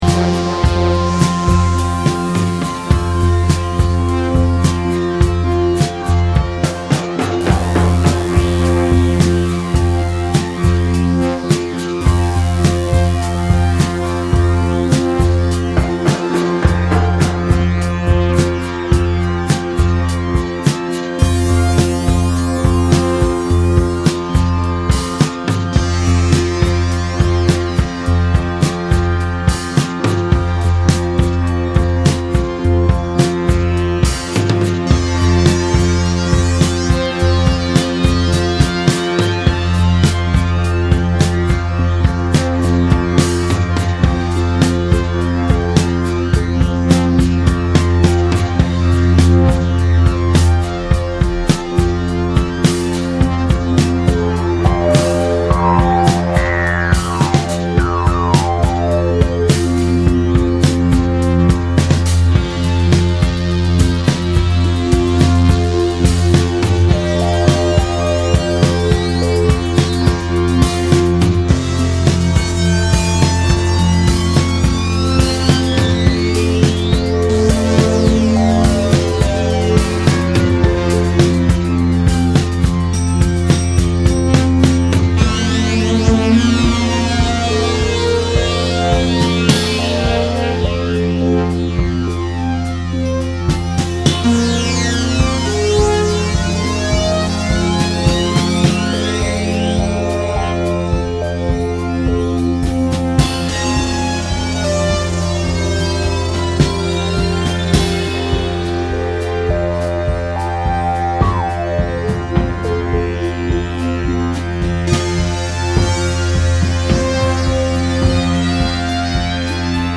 Cover versions
Disco Electro Electronix